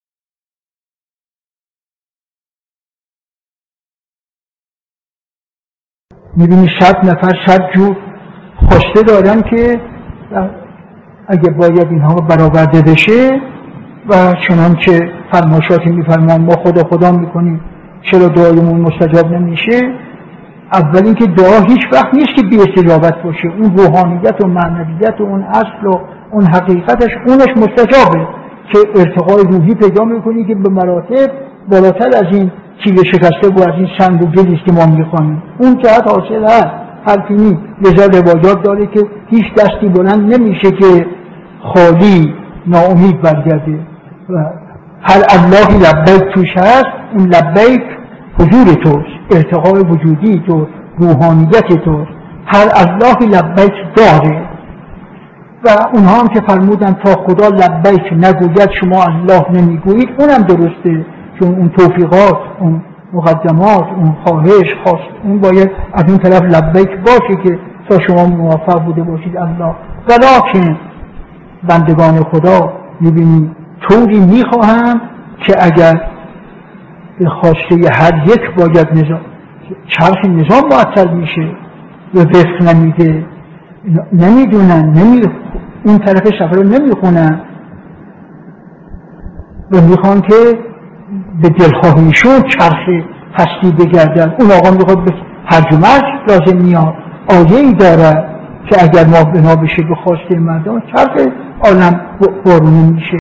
به گزارش خبرگزاری حوزه، مرحوم علامه حسن زاده آملی در یکی از جلسات اخلاق خود به موضوع «دعا همیشه بی‌پاسخ نیست» اشاره کردند که تقدیم شما فرهیختگان می شود.